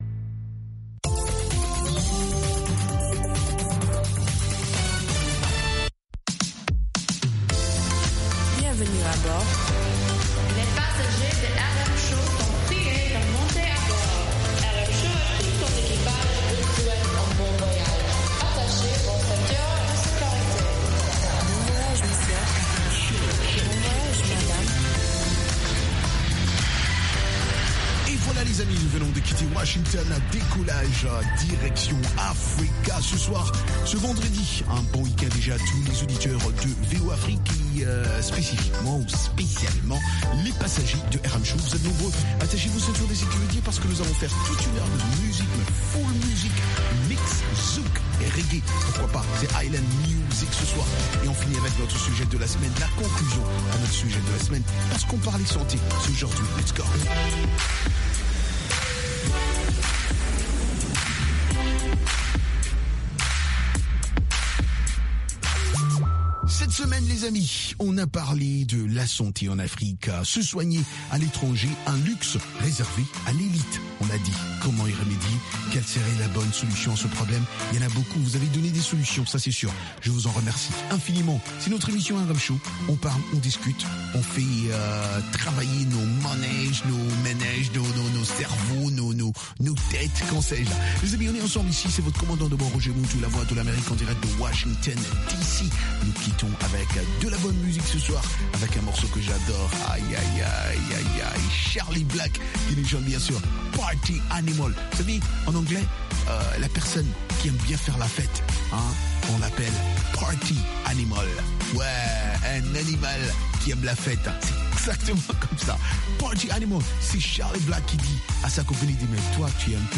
Ecoutez toute la musique des îles, Zouk, Reggae, Latino, Soca, Compas et Afro, et interviews de divers artistes